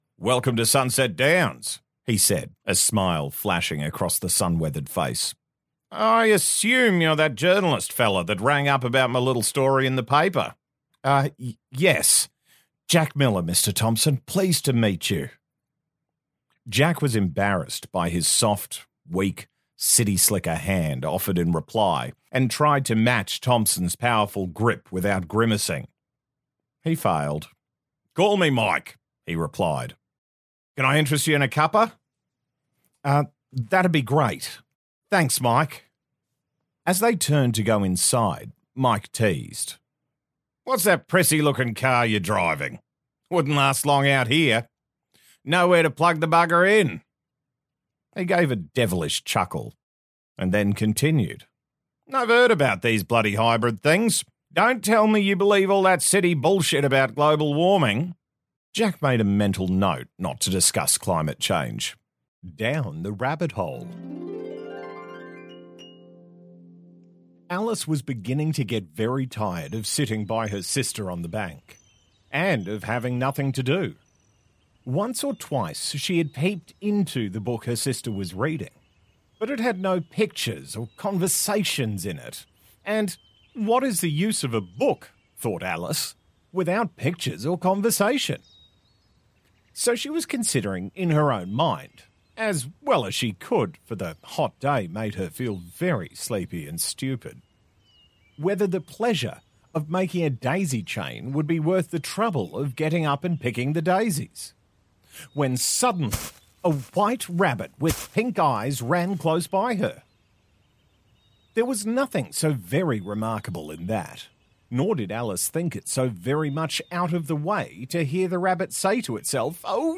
Male
Audiobooks
BaritoneBassDeepLow